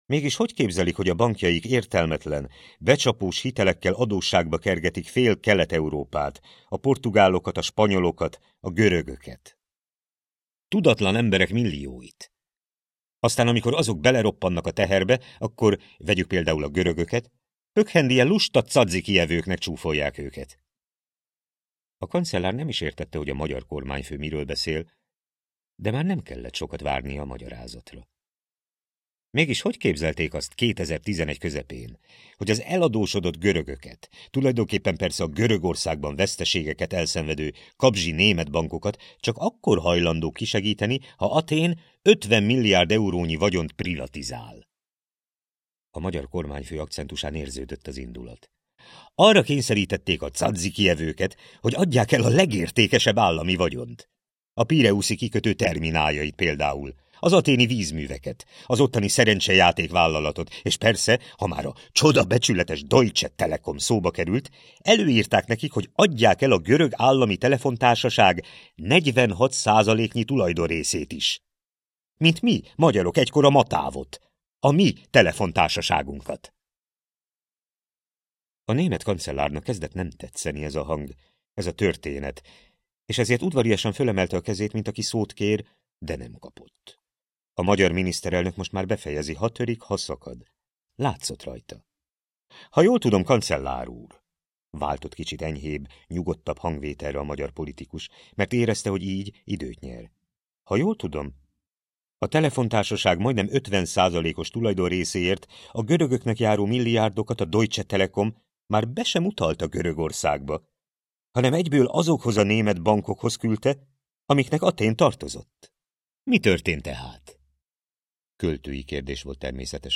(link) A hangoskönyv Viczián Ottó előadásában hallható.